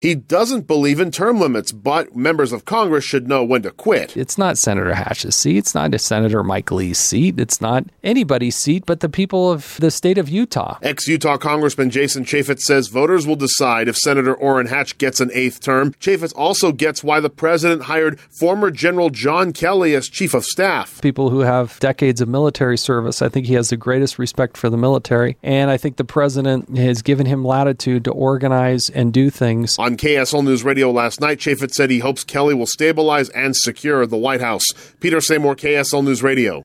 Jason Chaffetz, as a guest host on KSL Newsradio, opens up on a number of topics.